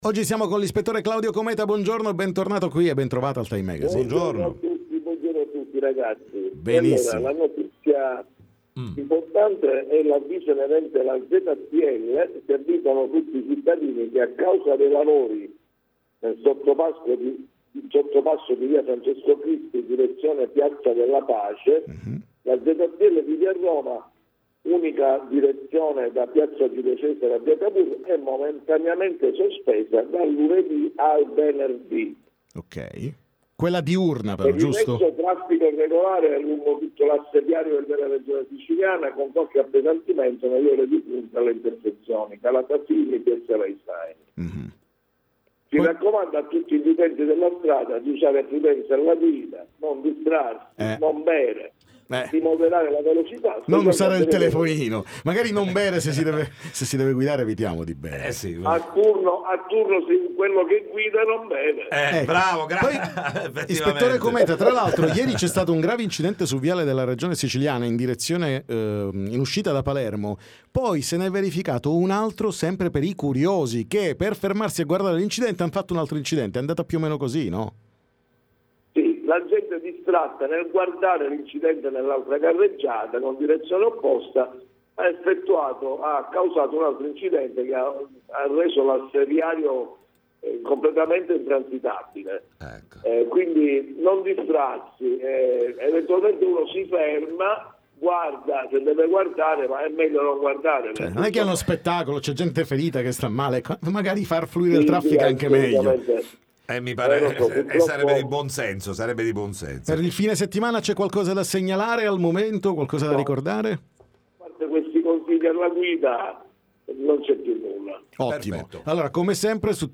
TM Intervista Polizia Municipale